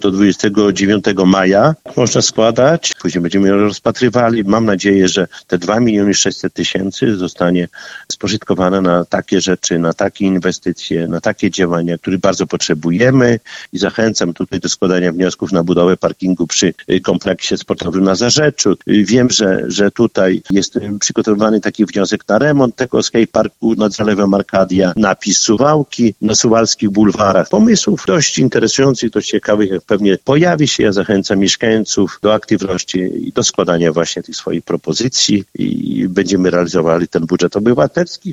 Jak się okazuje, są już pierwsze pomysły na to, jak wydać pieniądze. O szczegółach mówi Czesław Renkiewicz, prezydent Suwałk.